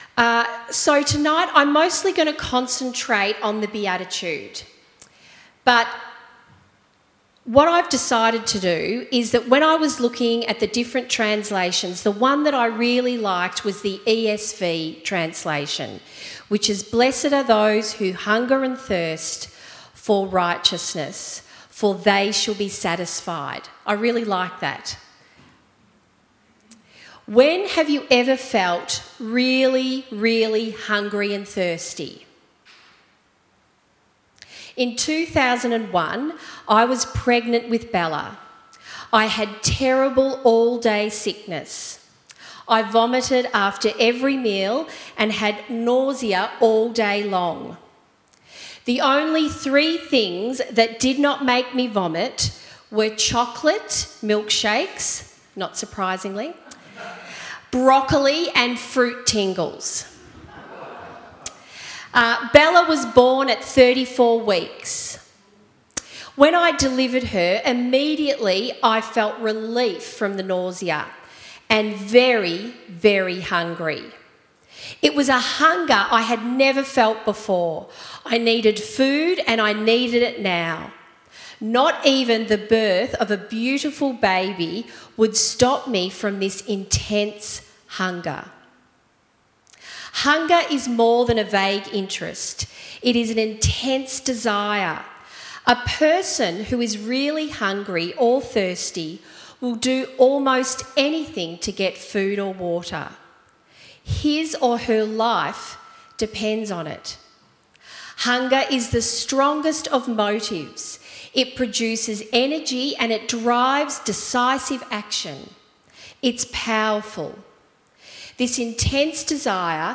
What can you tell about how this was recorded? at 5:30pm at Kew Campus